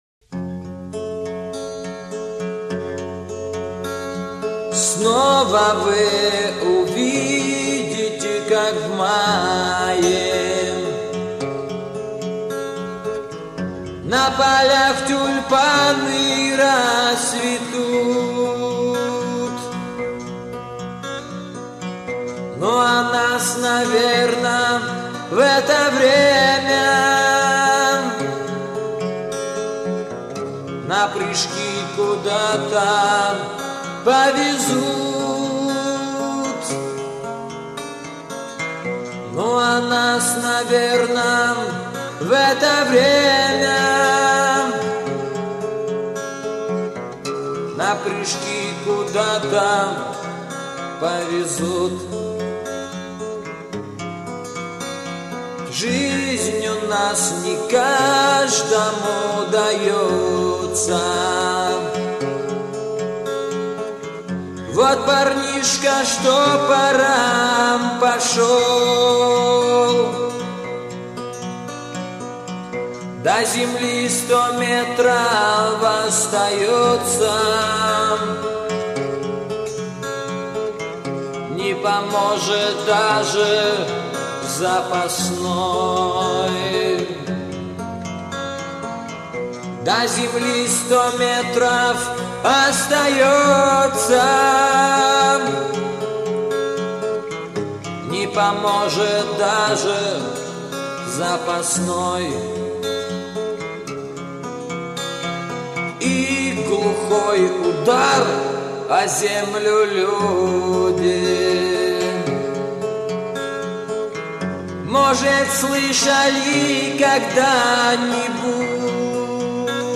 Гитара
Gitara.mp3